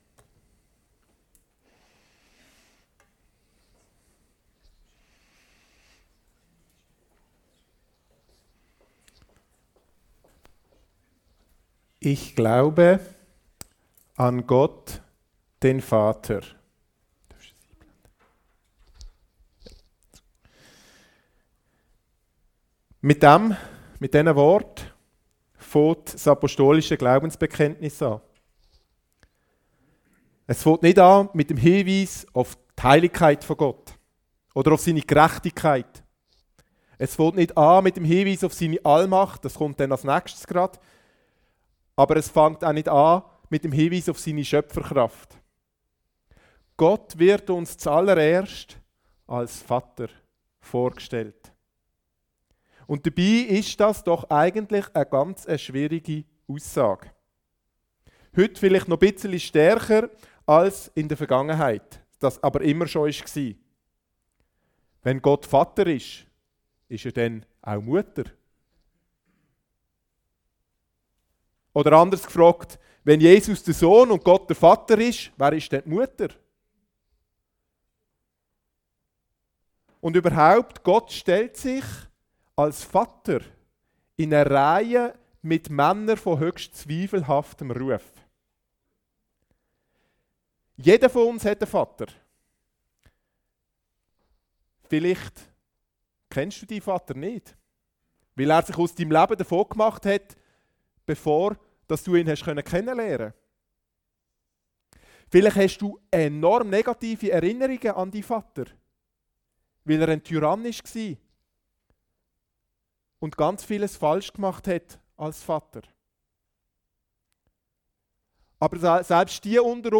Bible Text: Lukas 15:11-32 | Preacher